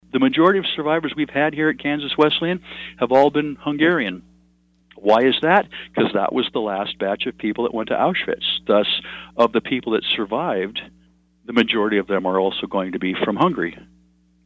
KSAL Morning News Extra